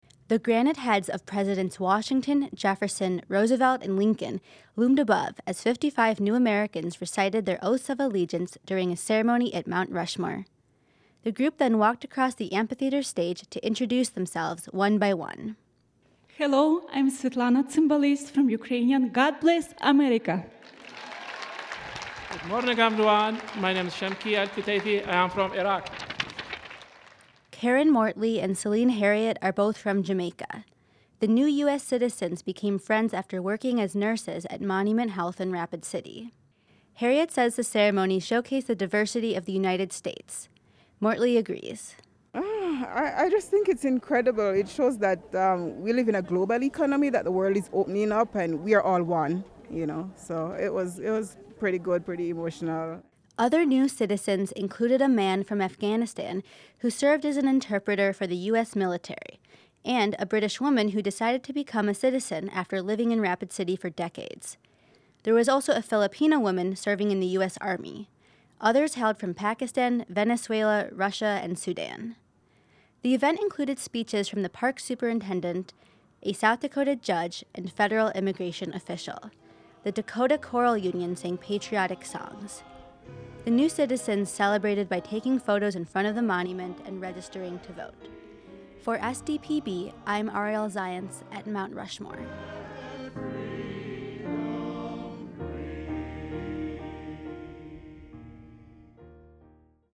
55 people from 29 countries became U.S. citizens during a naturalization ceremony at Mount Rushmore on Monday.